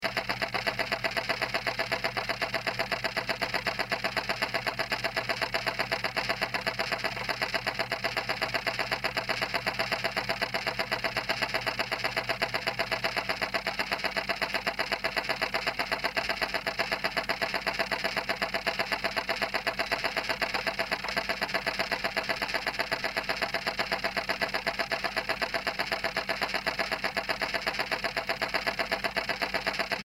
Звучание бытовых электронных часов (будильник)
Запись сделана с помощью гидрофона Соната-M и портативного рекордера Zoom H5.
sounds_hydrophone_Sonata-M.mp3